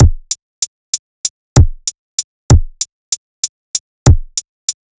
FK096BEAT2-R.wav